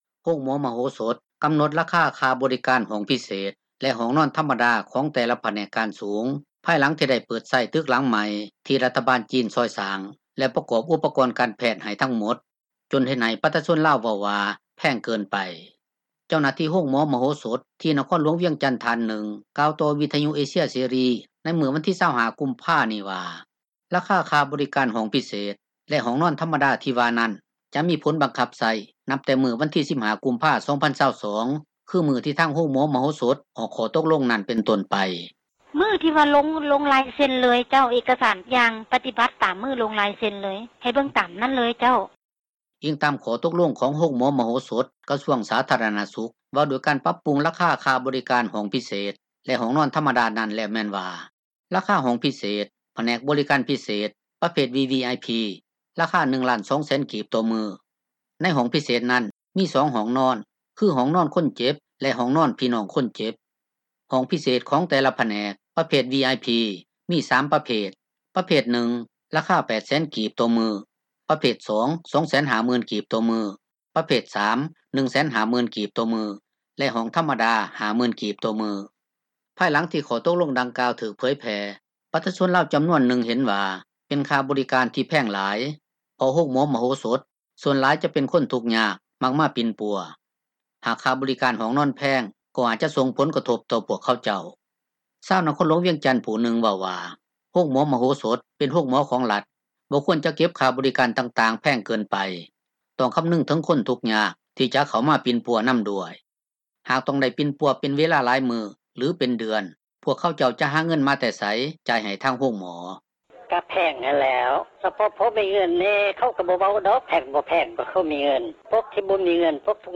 ເຈົ້າໜ້າທີ່ໂຮງໝໍມະໂຫສົດ ທີ່ນະຄອນຫລວງວຽງຈັນ ທ່ານນຶ່ງກ່າວຕໍ່ວິທຍຸເອເຊັຽເສຣີໃນມື້ ວັນທີ 25 ກຸມພານີ້ວ່າ ລາຄາຄ່າບໍຣິການຫ້ອງພິເສດ ແລະຫ້ອງນອນທັມມະດາ ທີ່ວ່ານັ້ນ ຈະມີຜົນບັງຄັບໃຊ້ນັບແຕ່ມື້ວັນທີ 15 ກຸມພາ 2022 ຄືມື້ທີ່ທາງໂຮງໝໍມະໂຫສົດ ອອກຂໍ້ຕົກລົງນັ້ນເປັນຕົ້ນໄປ.
ຊາວນະຄອນຫລວງວຽງຈັນຜູ້ນຶ່ງເວົ້າວ່າ ໂຮງໝໍມະໂຫສົດ ເປັນໂຮງໝໍຂອງຣັຖ, ບໍ່ຄວນຈະເກັບຄ່າບໍຣິການຕ່າງໆແພງເກີນໄປ, ຕ້ອງຄໍານຶງໄປເຖິງຄົນທຸກຍາກ ທີ່ຈະເຂົ້າມາປິ່ນ ປົວນໍາດ້ວຍ.